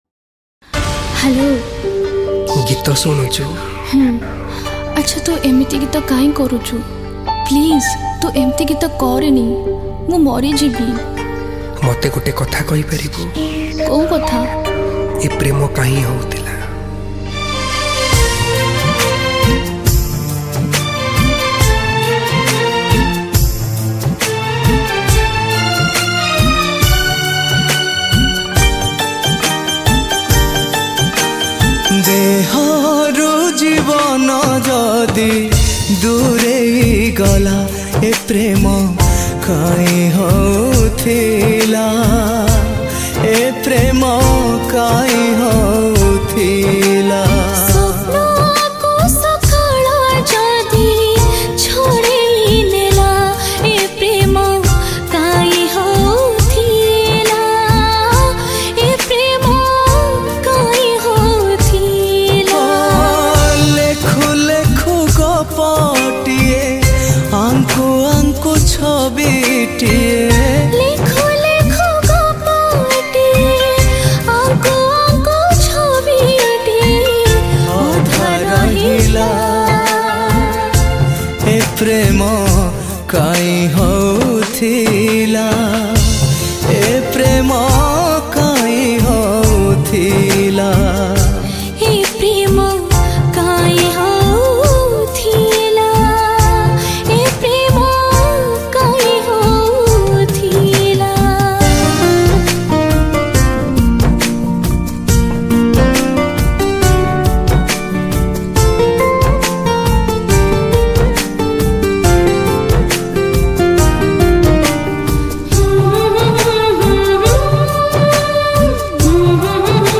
Broken Heart Song
New Odia Album Songs